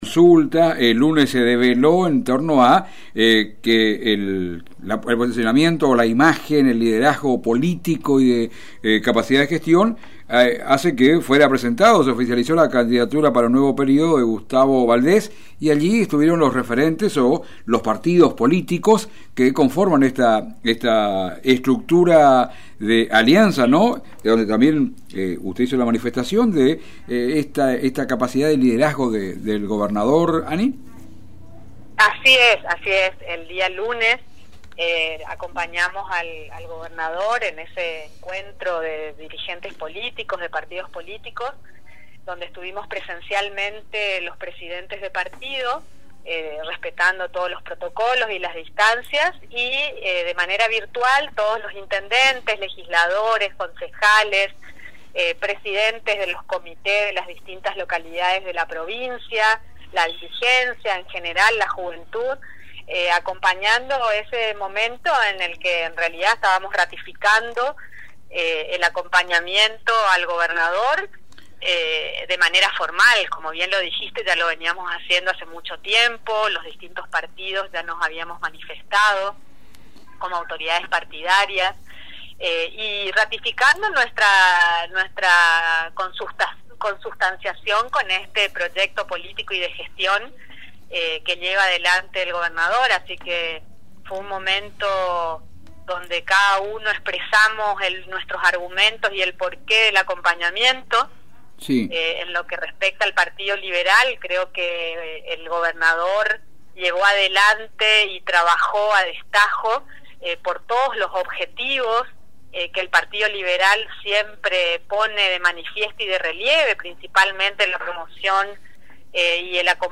diálogo este miércoles en exclusiva con LT6 Radio Goya